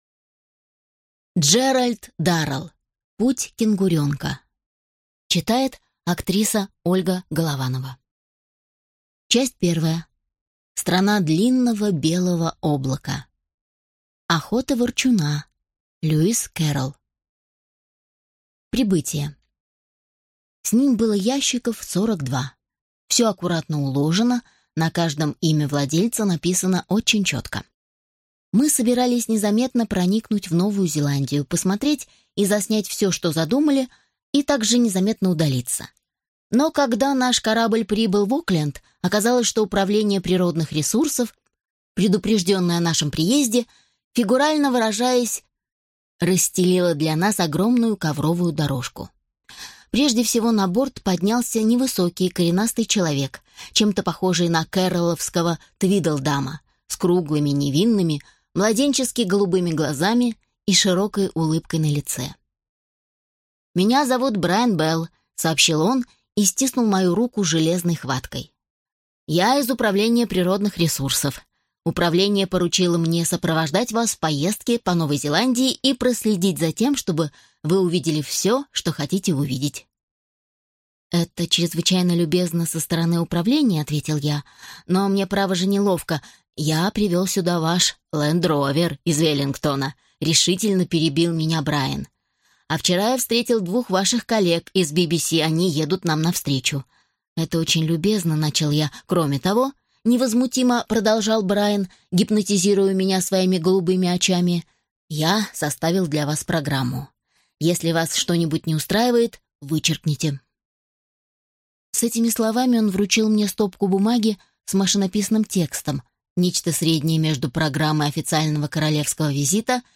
Аудиокнига Путь кенгуренка | Библиотека аудиокниг